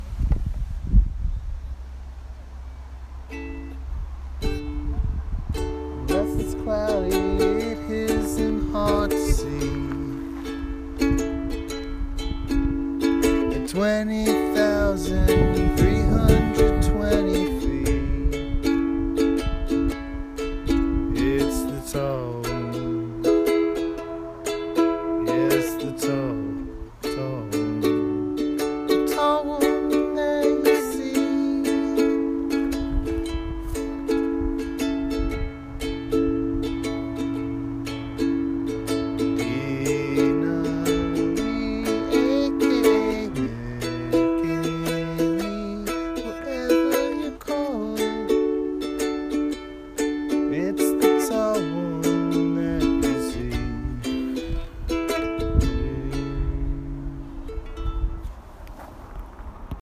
Field Recordings